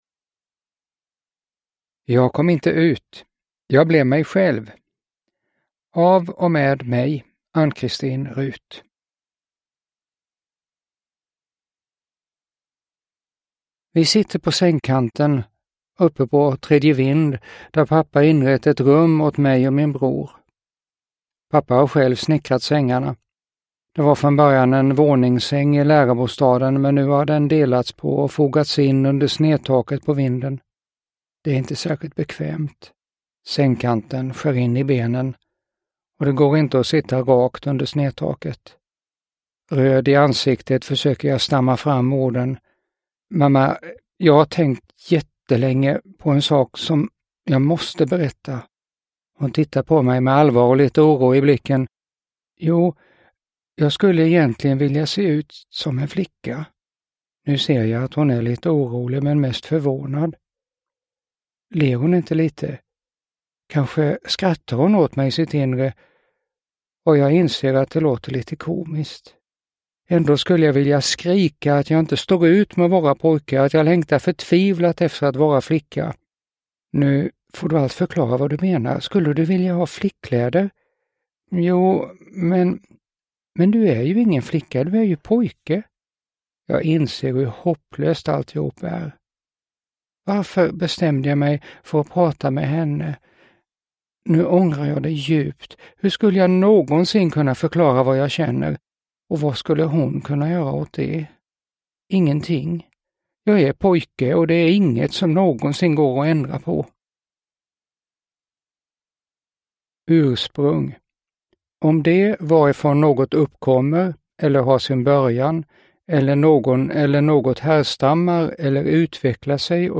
Jag kom inte ut - jag blev mig själv – Ljudbok – Laddas ner